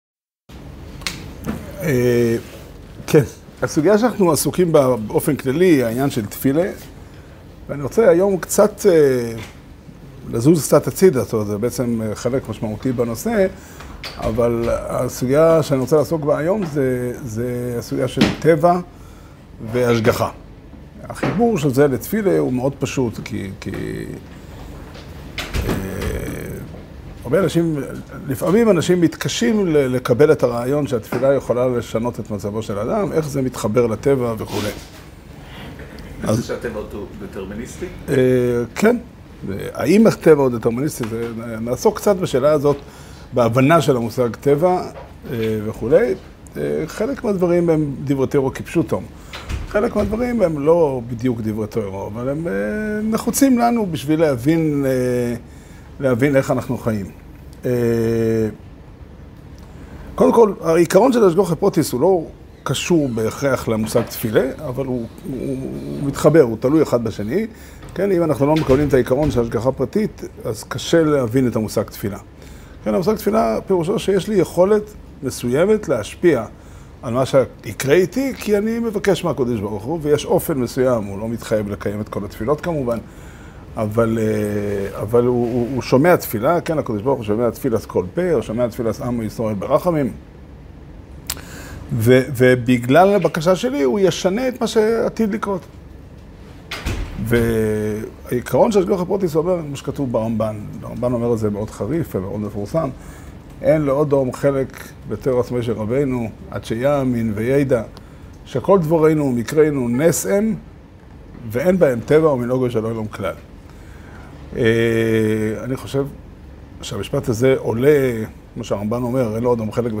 שיעור שנמסר בבית המדרש 'פתחי עולם' בתאריך ה' תמוז תשפ"ד